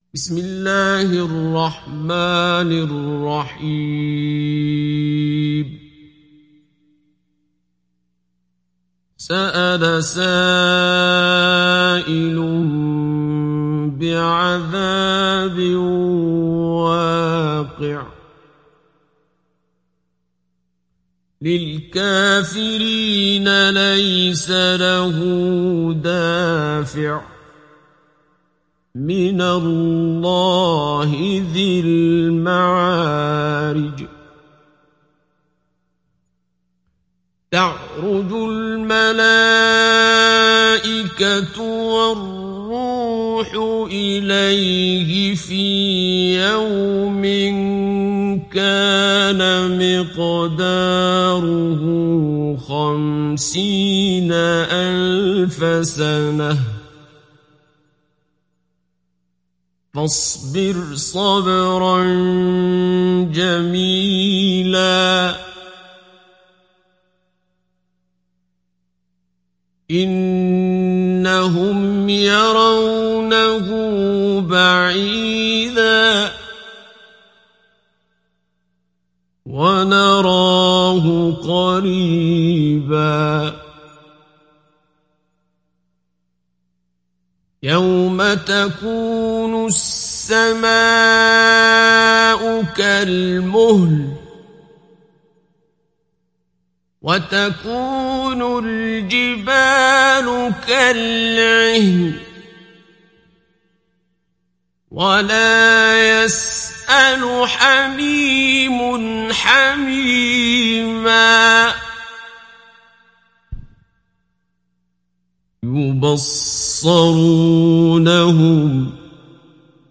Surah Al Maarij mp3 Download Abdul Basit Abd Alsamad Mujawwad (Riwayat Hafs)